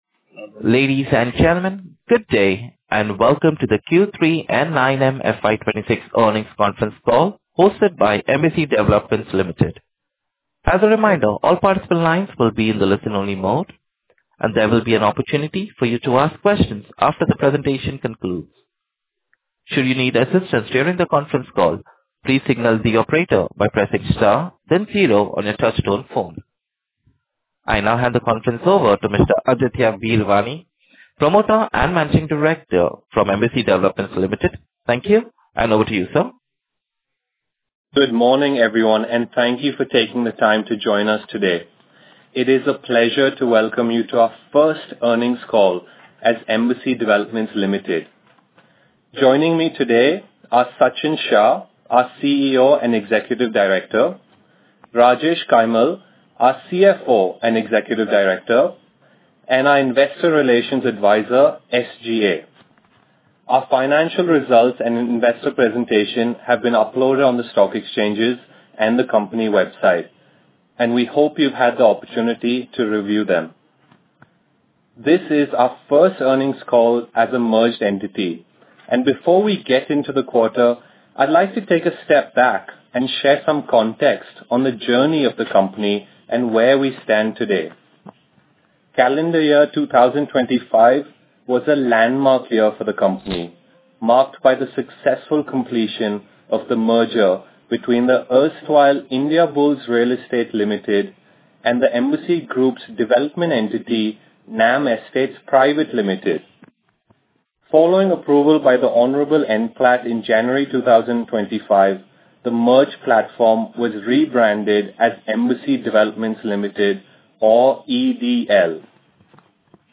EDL Earnings Conference Call Recording Q3FY2026